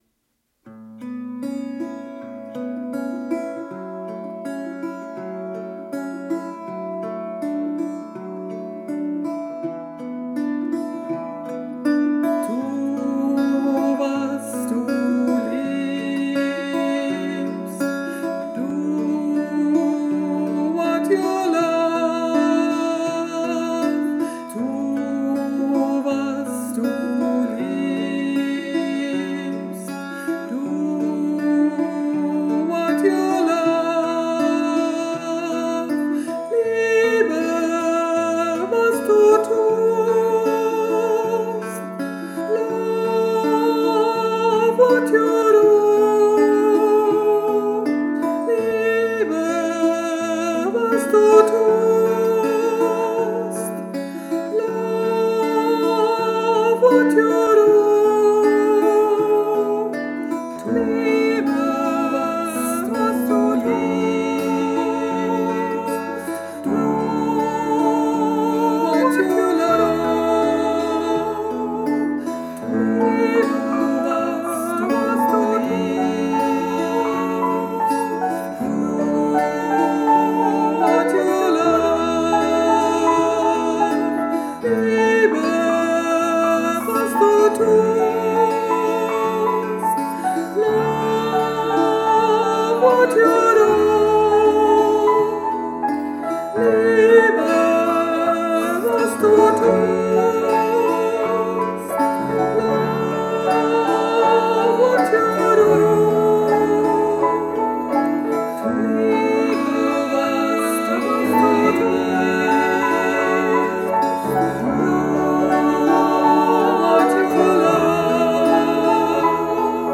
Tu was du liebst Piano_2020.mp3